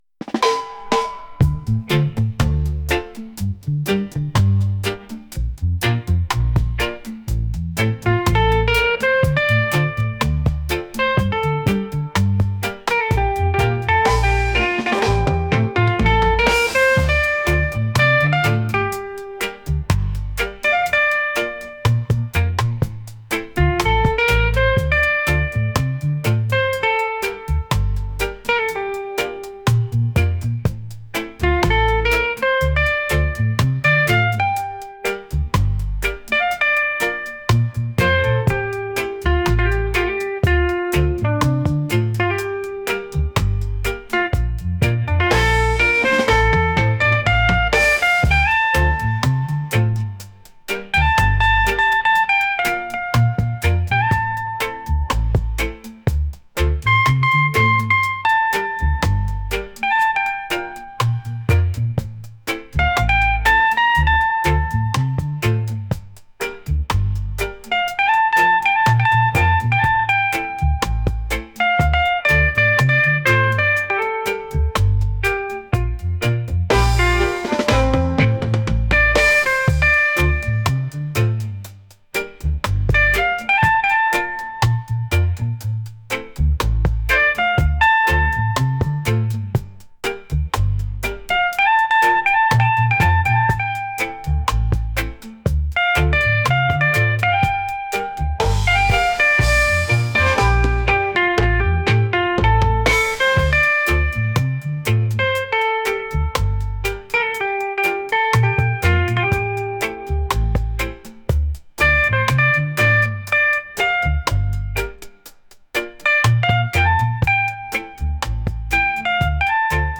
reggae | soul